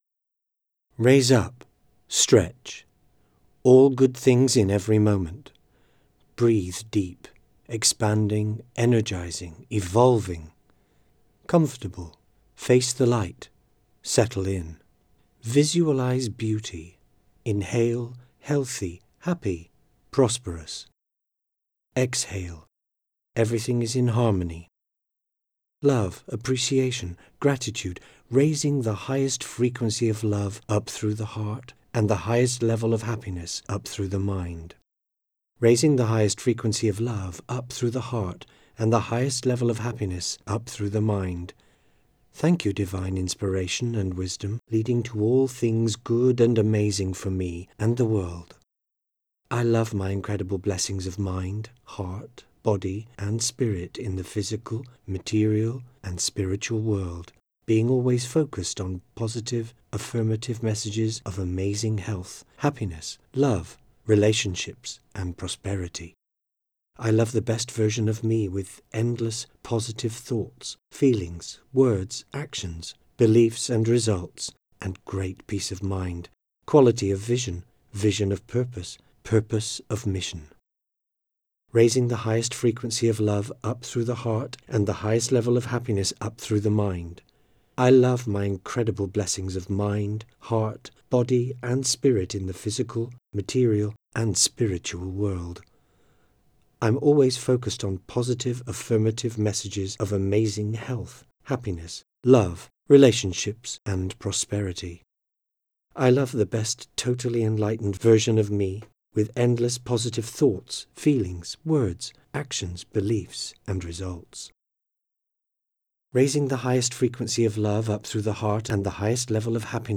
General UK & RP
I have my own home studio and can record with a regionally neutral UK accent or if needed a Heightened RP.
Meditation Source Living